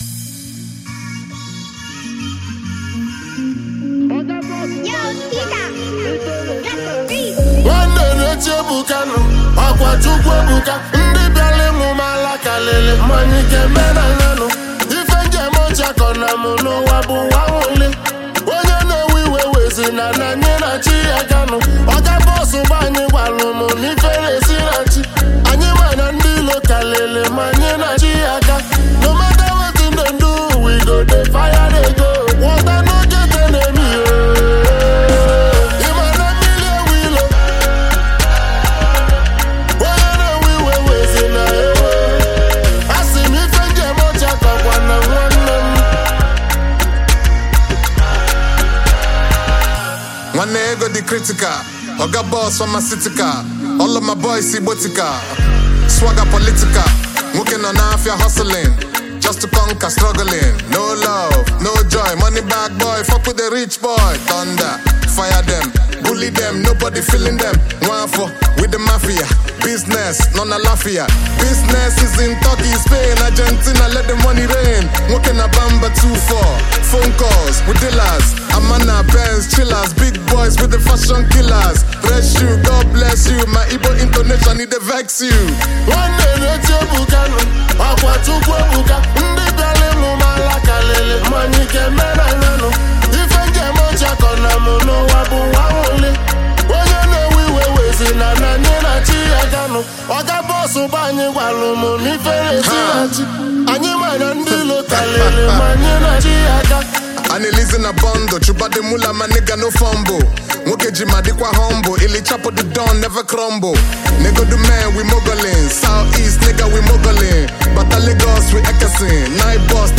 a famous and talented Nigerian highlife music duo.